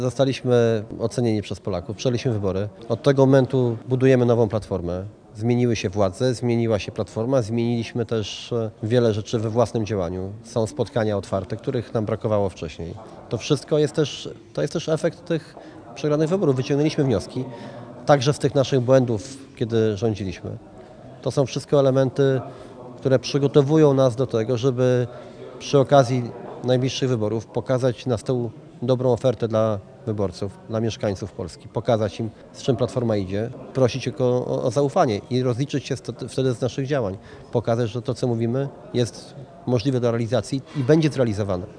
Do tych słów odniósł się również Sławomir Neumann.